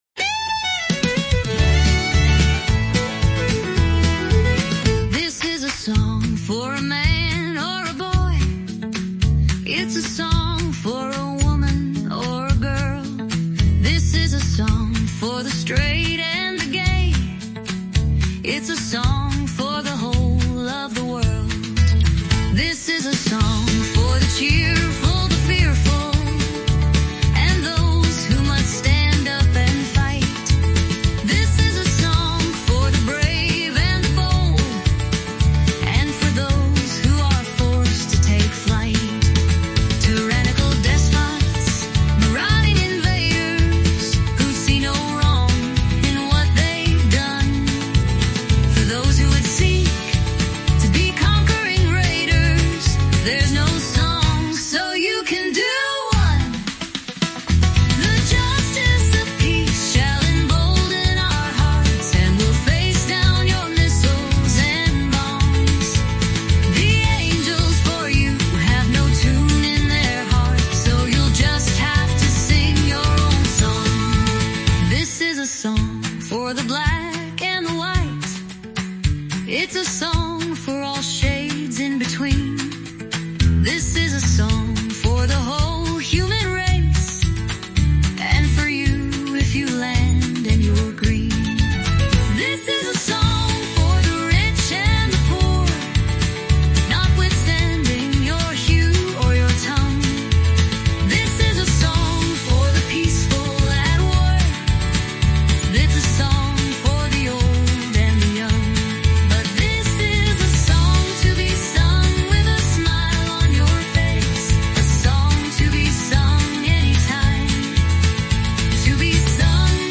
then had a running battle with the robots
that insisted 'Heartwarming' was pronounced 'Heartwerming' and ' Vive la difference'
wonderful, upbeat song!
I can however, shape an AI tune by judicious spelling, punctuation and even apparently superfluous line breaks.